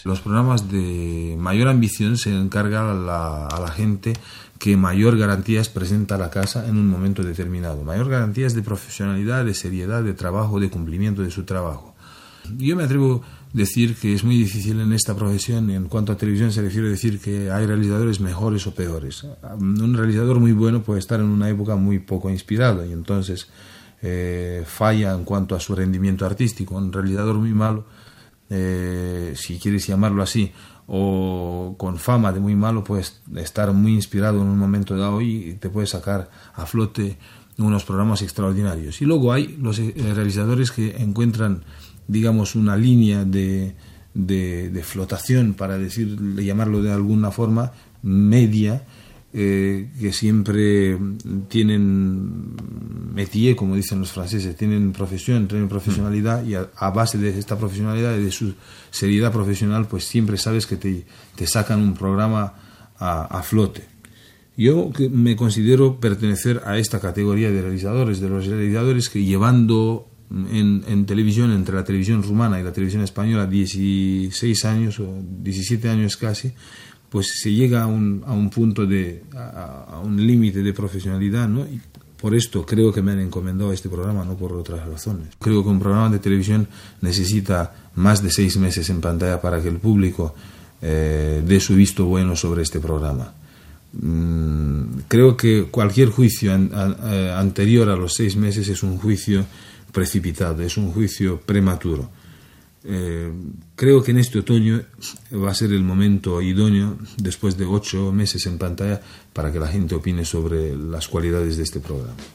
El realitzador Valerio Lazarov parla de la seva feina, de l'ofici i del nou programa de Televisió Espanyola ¡Señoras y señores!